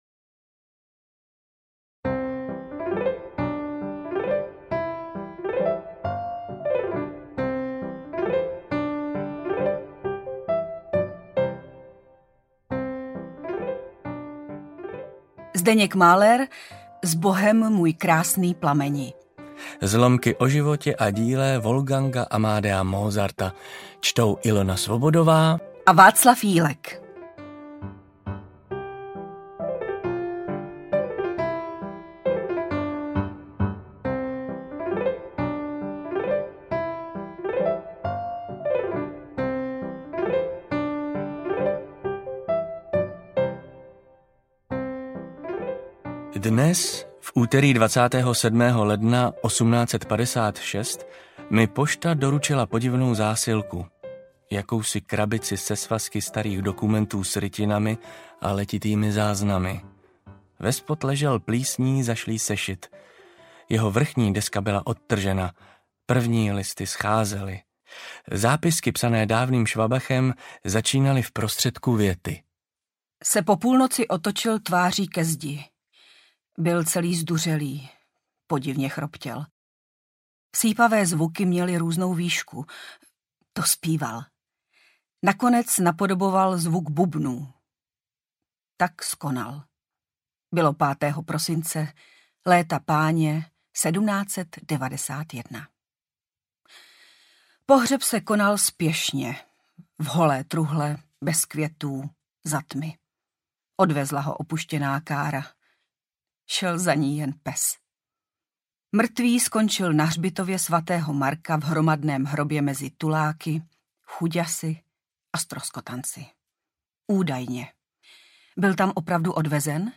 Sbohem, můj krásný plameni / Zlomky o životě a díle W. A. Mozarta - Zdeněk Mahler - Audiokniha